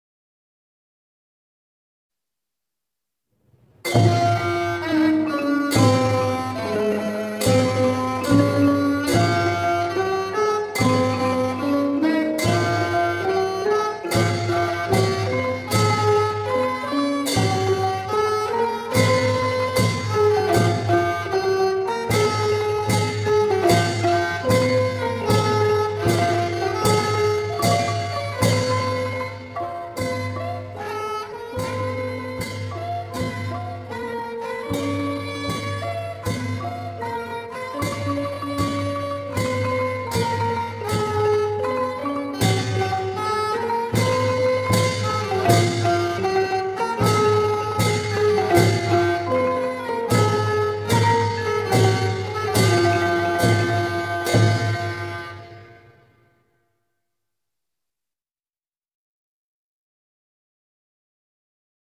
วงปี่พาทย์-ไม้นวม